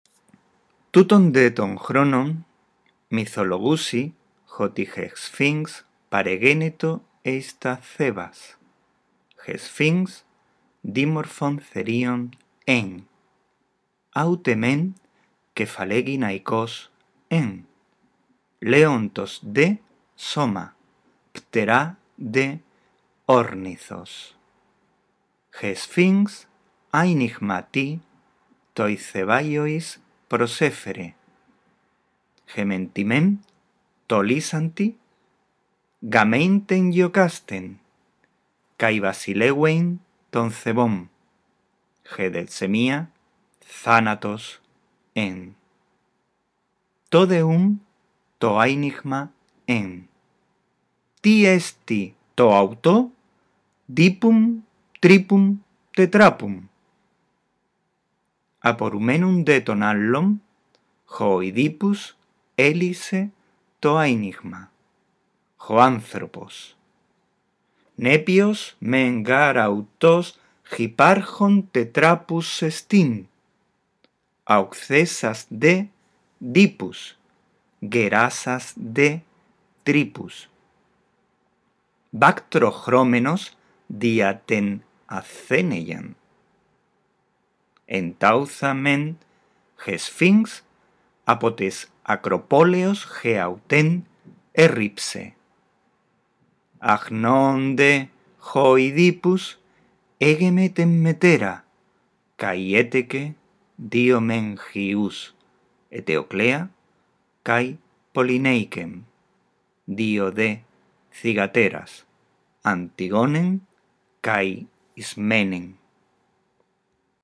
Como siempre, aquí tienes un archivo con la lectura del desenlace de la primera parte de la historia de Edipo.